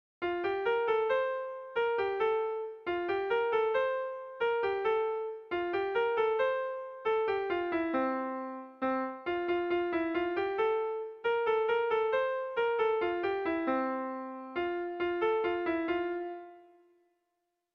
Erromantzea
AABD